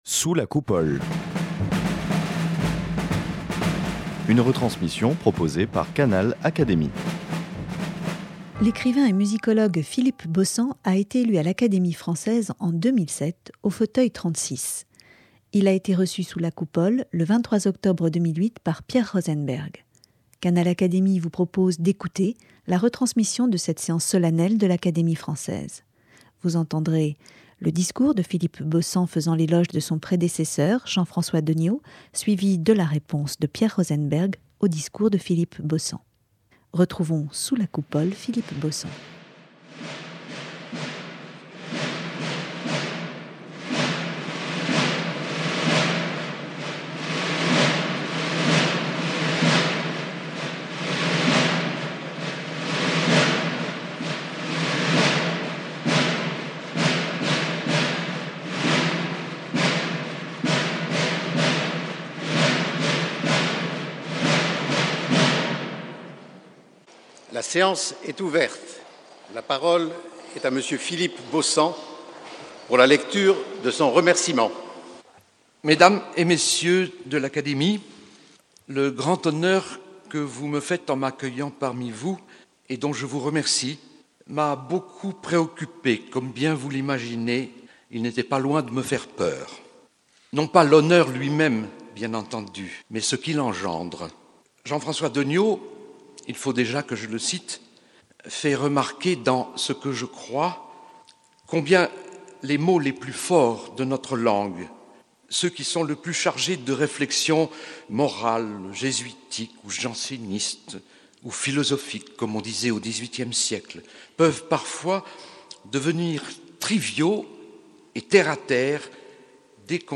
Philippe Beaussant a été reçu sous la Coupole, le jeudi 23 octobre 2008 par Pierre Rosenberg, au fauteuil de Jean-François Deniau. Canal Académie vous propose d’écouter la retransmission de cette séance solennelle de l’Académie française.